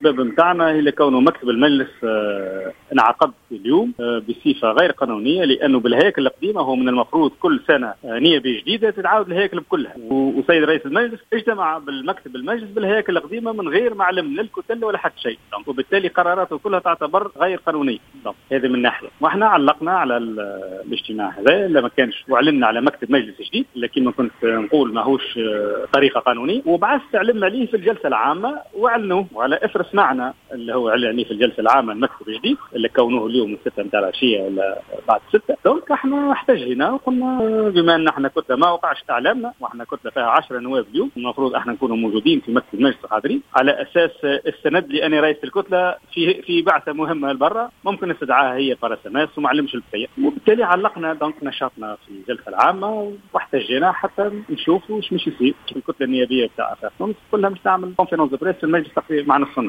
أكد النائب عن حزب آفاق تونس،حافظ الزواري في تصريح ل"الجوهرة أف أم" أن كتلة آفاق تونس قرّرت تعليق نشاطها داخل مجلس النواب.